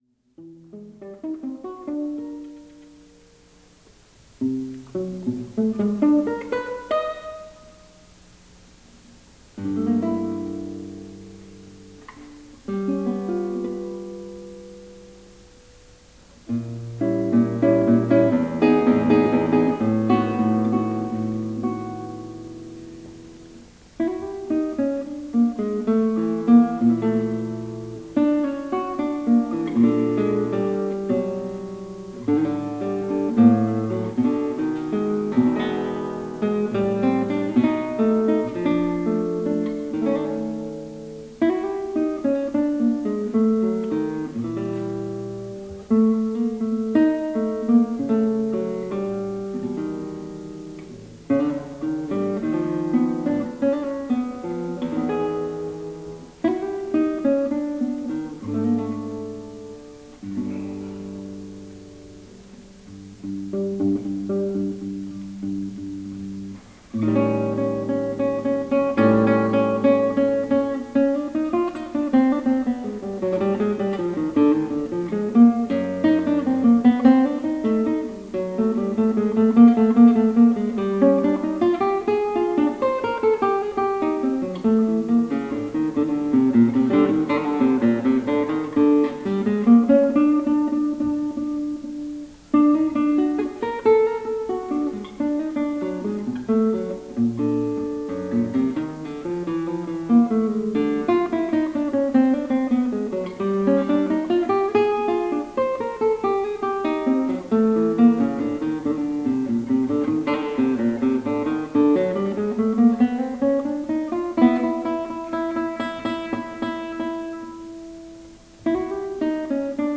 Solo Music, Soul jazz, Contemporary Christian